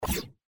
UI_CloseWindow_01.mp3